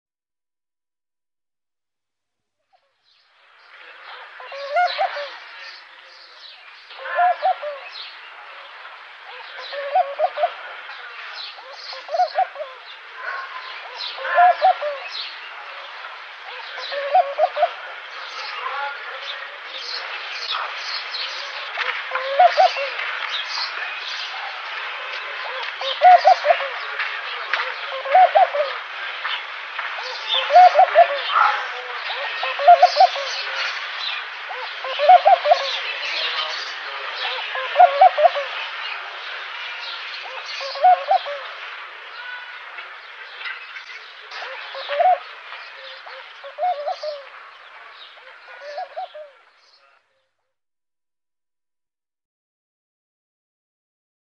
Palmduva / Laughing Dove Spilopelia senegalensis Läte / Sound Du är här > Fåglar / Birds > Palmduva / Laughing Dove Galleri med utvalda fågelbilder / Favourites Dubai, oktober 2024.
Palmduva.mp3